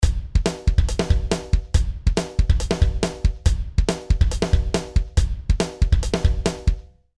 Drums
Drums_no_effect.mp3